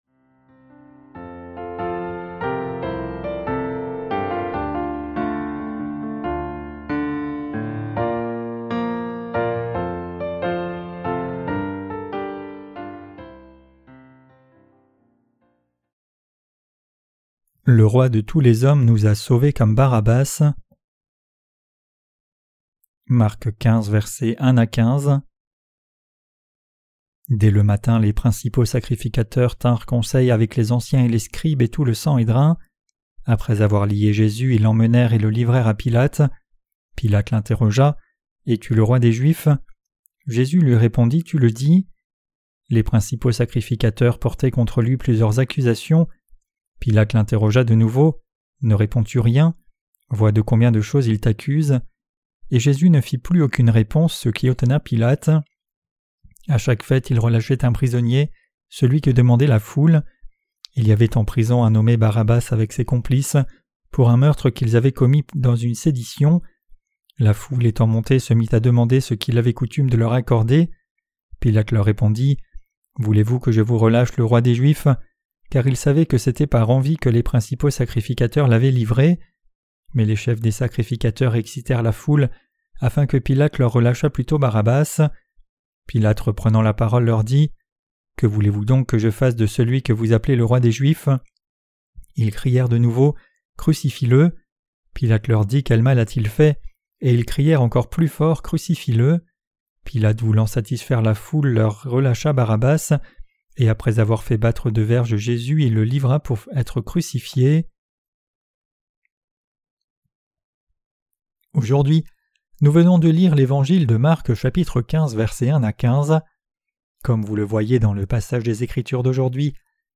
Sermons sur l’Evangile de Marc (Ⅲ) - LA BÉNÉDICTION DE LA FOI REÇUE AVEC LE CŒUR 10.